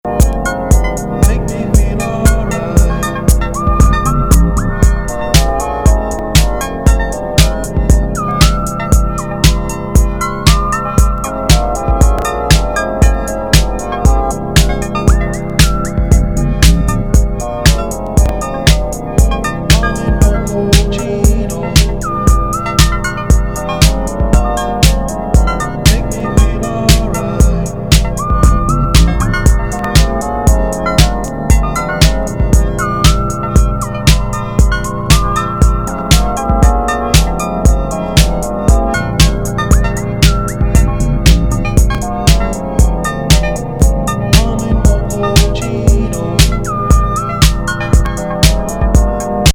デトロイト・テクノ
コチラも人気!フロア・トラックというよりは楽曲性の高いエレクトロニクス・